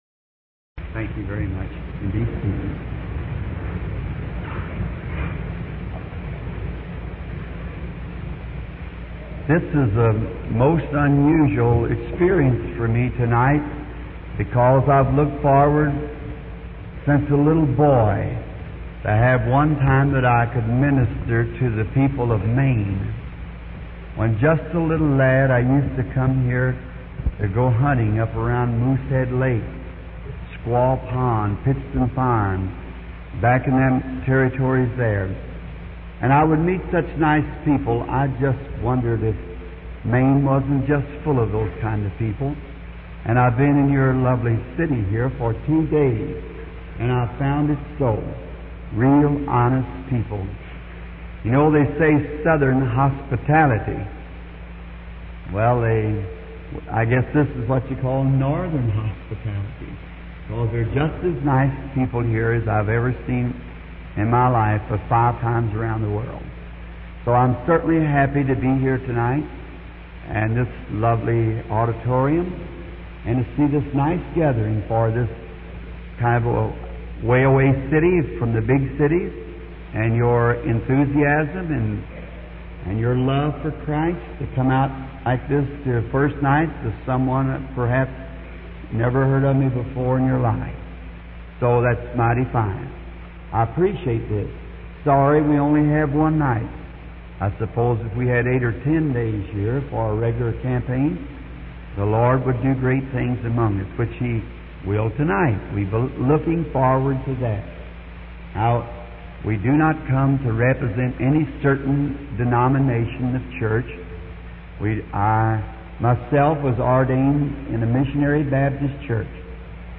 Dieses Portal gibt Ihnen die Möglichkeit, die ca. 1200 aufgezeichneten Predigten von William Marrion Branham aufzurufen, zu lesen oder zu hören.